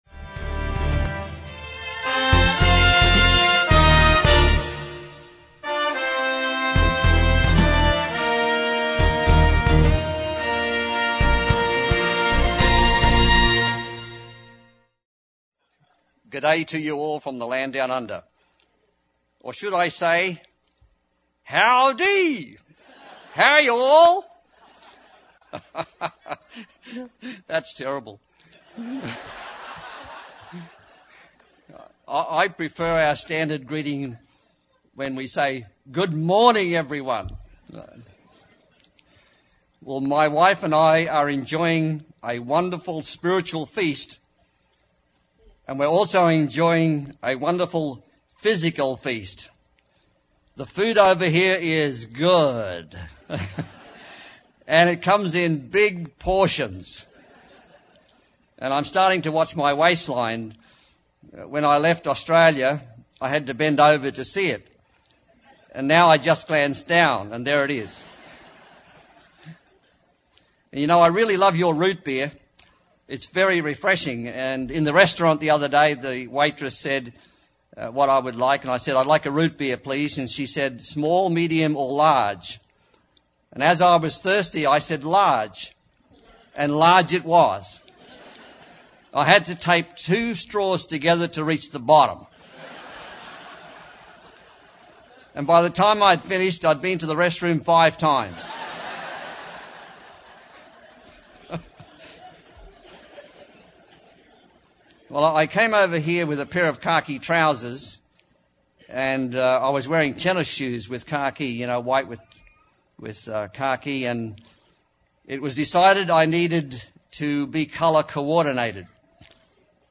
This sermon was given at the Branson, Missouri 2006 Feast site.